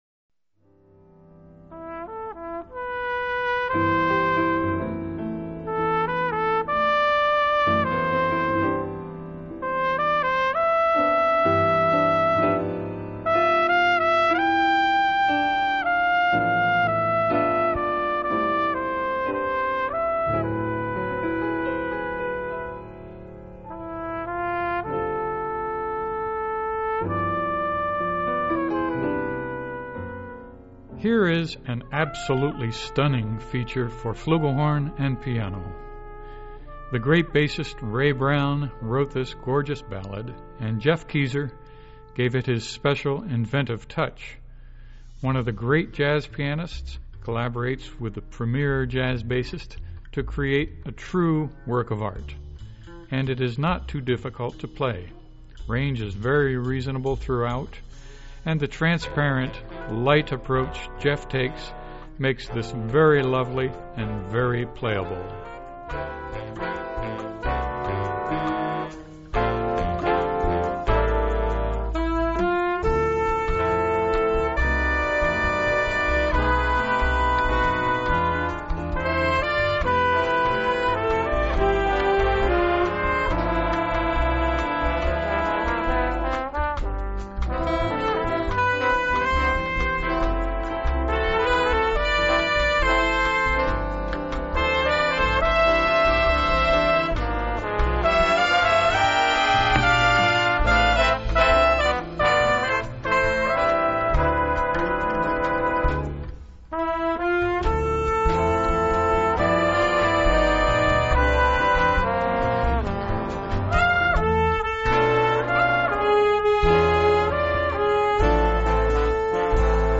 publisher's sample
flugelhorn and piano